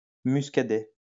Muscadet (UK: /ˈmʌskəd, ˈmʊsk-/ MU(U)SK-ə-day, US: /ˌmʌskəˈd, ˌmʊsk-/ MU(U)SK-ə-DAY, French: [myskadɛ]